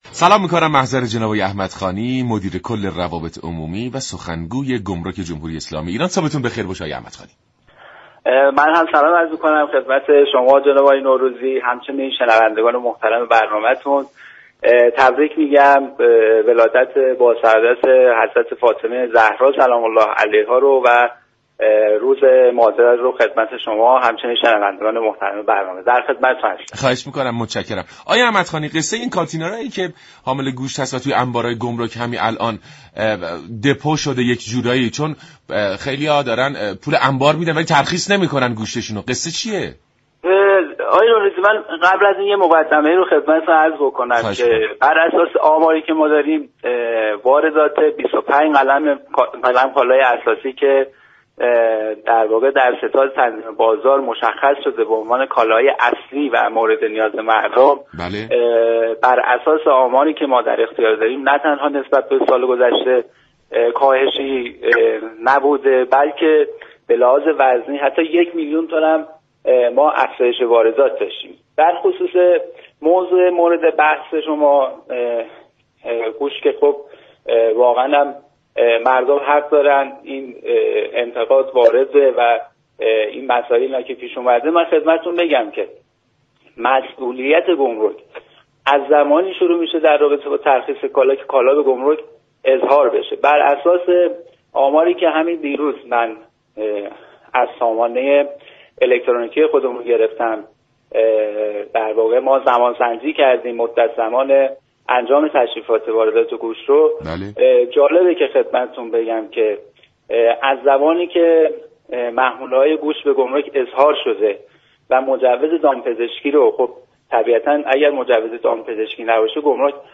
این گفت و گو را در ادامه باهم می شنویم.